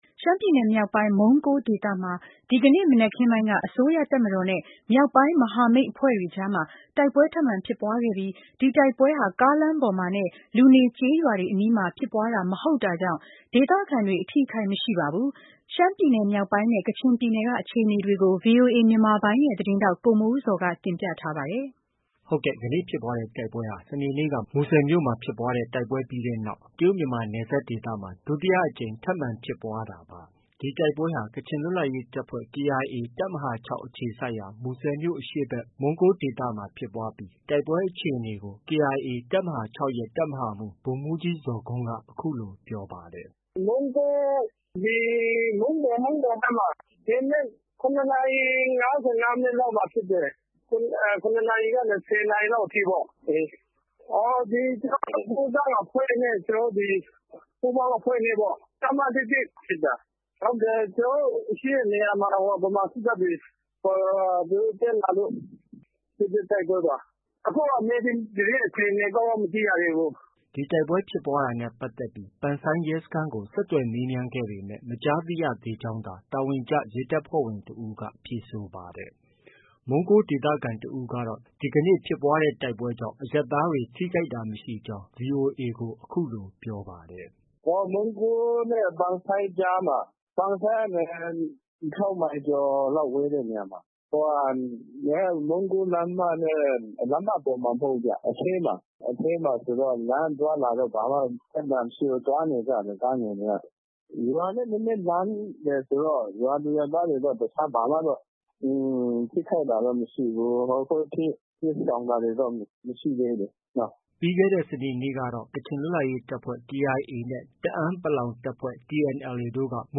မုန်းကိုးဒေသခံတဦးကတော့ ဒီကနေ့ဖြစ်ပွားတဲ့တိုက်ပွဲကြောင့် အရပ်သားတွေ ထိခိုက်တာမရှိဘူးလို့ VOA ကို ပြောပါတယ်။